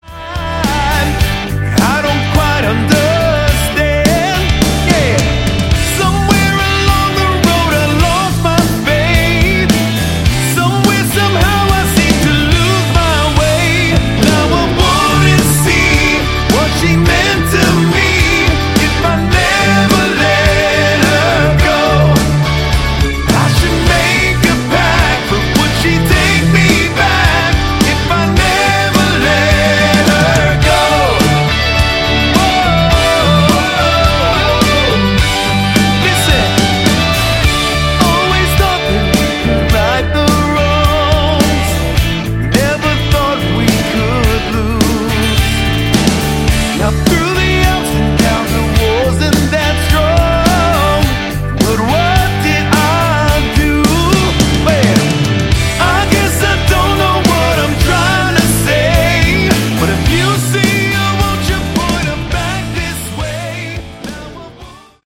Category: Melodic Rock